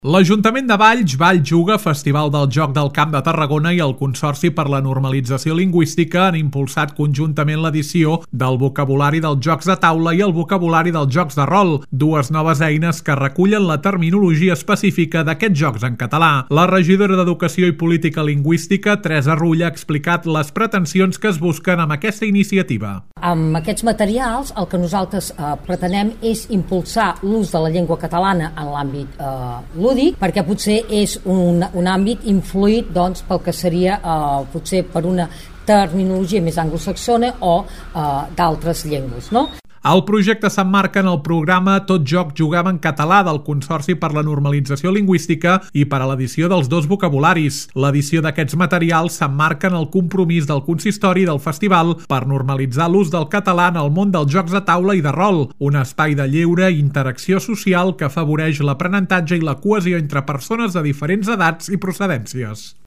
La regidora d’Educació i Política Lingüística, Teresa Rull, ha explicat les pretensions que es busquen amb aquesta iniciativa.